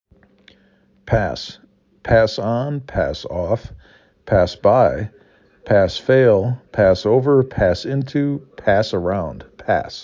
4 Letters, 1 Syllable
p a s